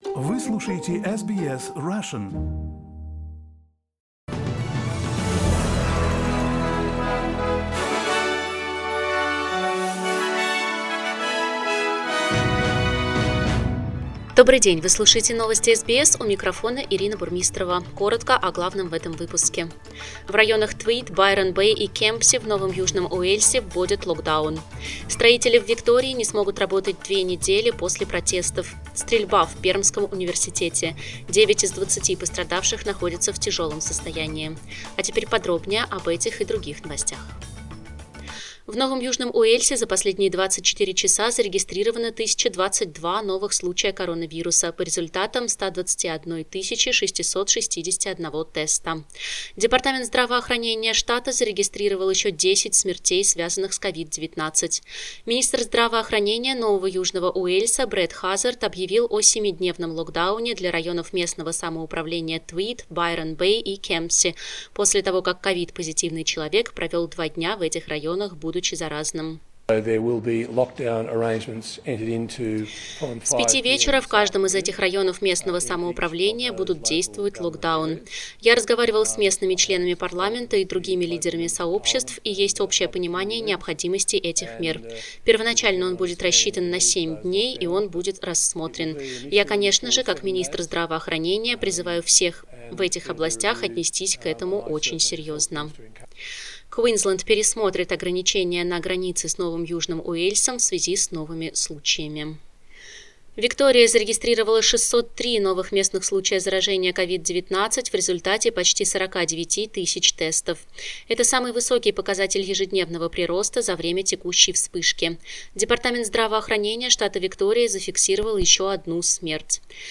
SBS news in Russian - 21.09